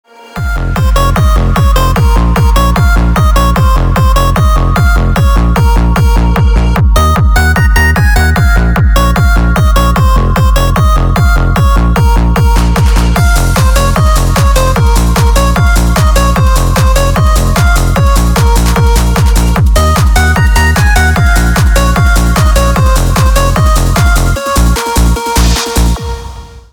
Веселые мелодии